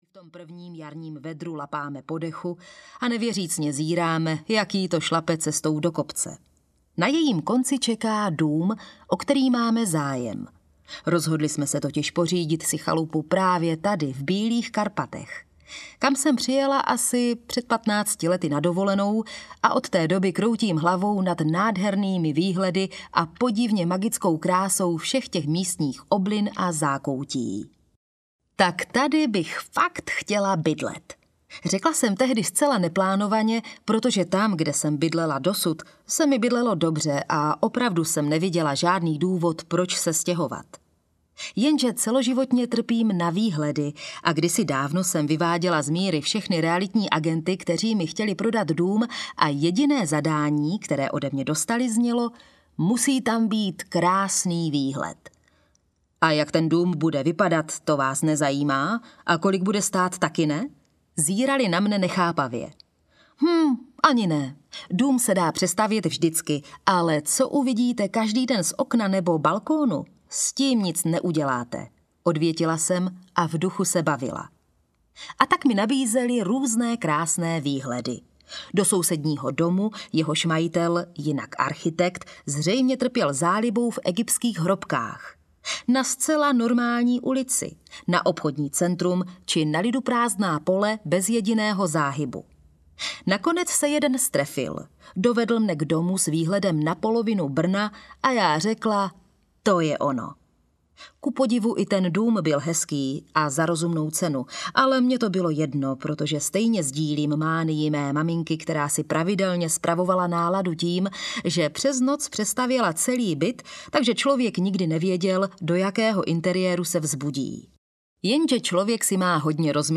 Z kopce do kopce audiokniha
Ukázka z knihy